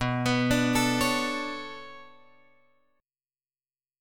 B Minor 9th